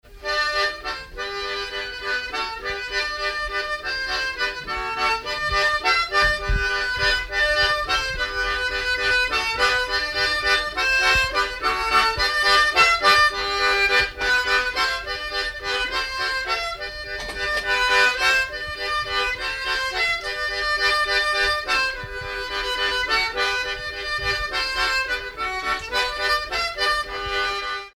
Valse
danse : valse
circonstance : bal, dancerie
Pièce musicale inédite